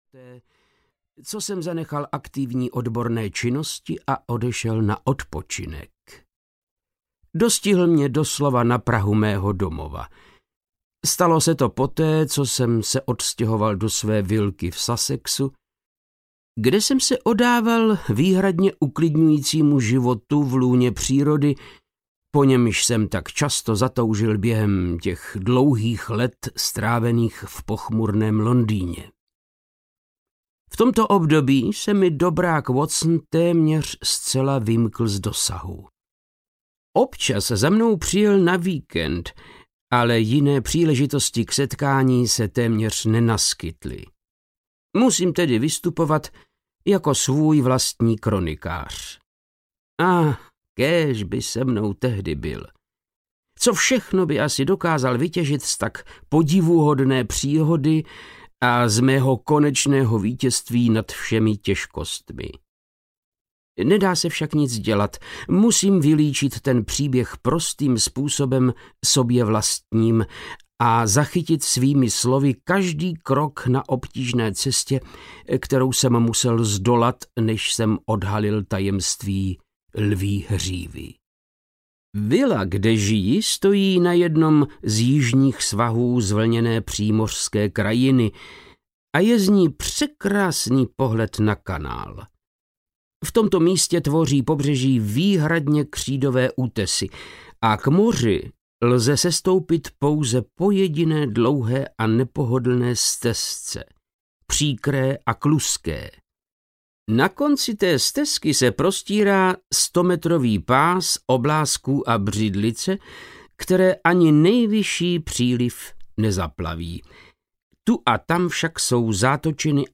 Lví hříva audiokniha
Ukázka z knihy
• InterpretVáclav Knop